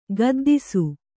♪ gaddisu